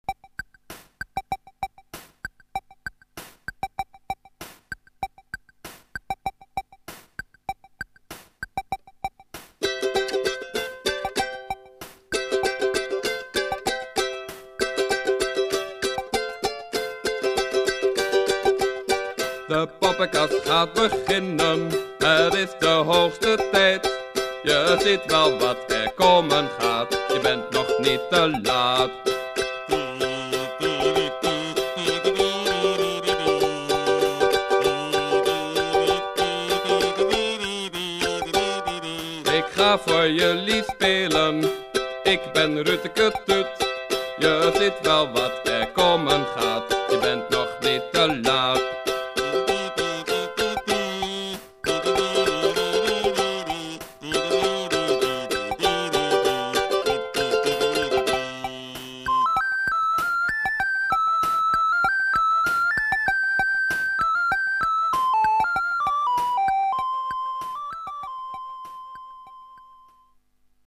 Liedje